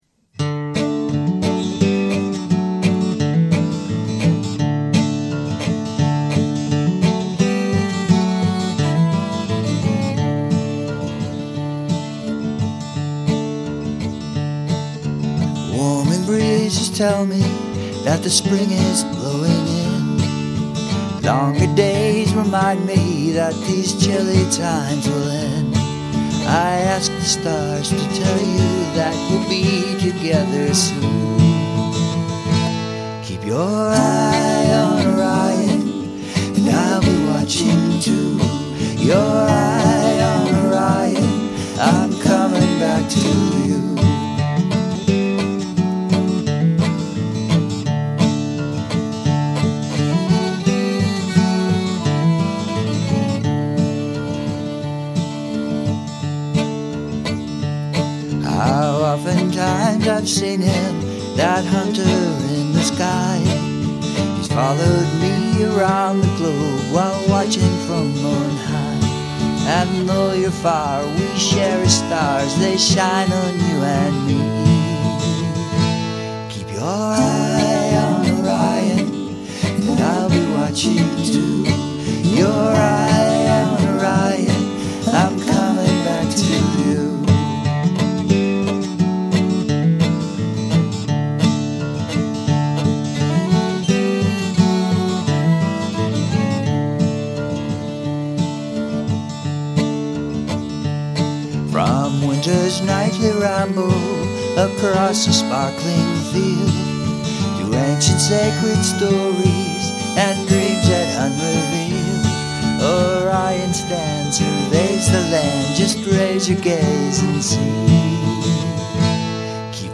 folk rock originals
New Mexico School of Music
"unplugged."